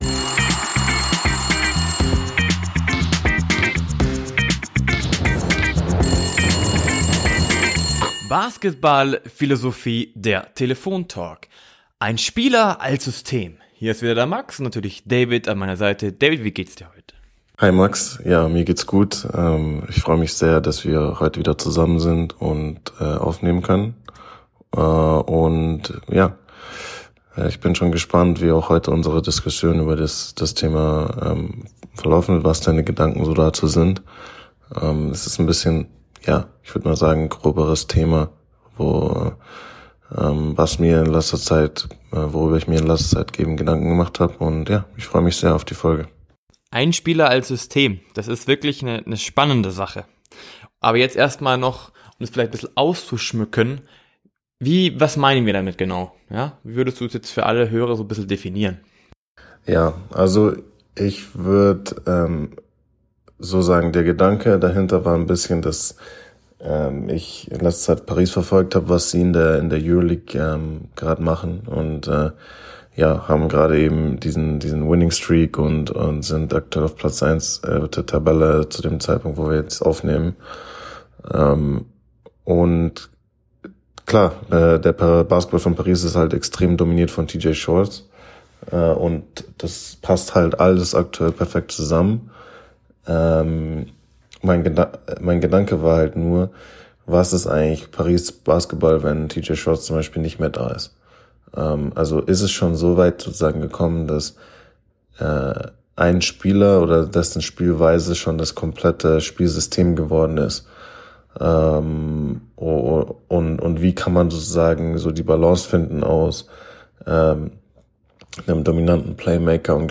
Ein Spieler als System ~ Basketballphilosophie: Der Telefontalk Podcast